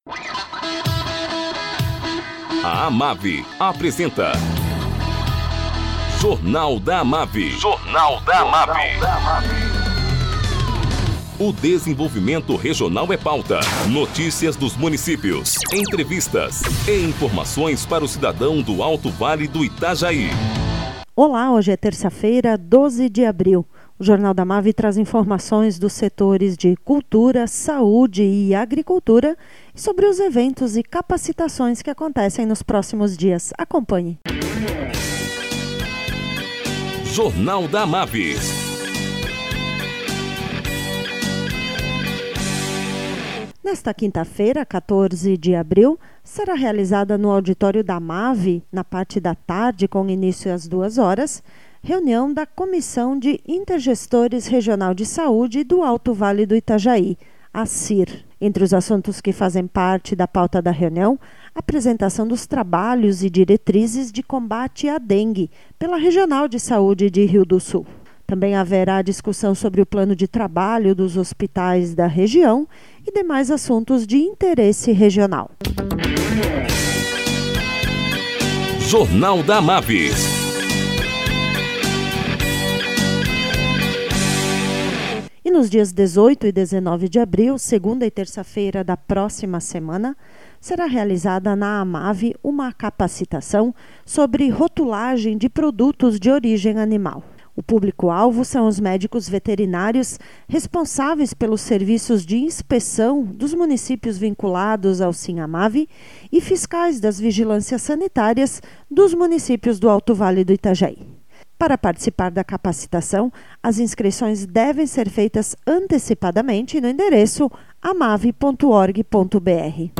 Prefeito de Rio do Sul, José Thomé fala sobre o prêmio Prefeito Empreendedor do Sebrae/SC, que recebeu ontem em Florianópolis.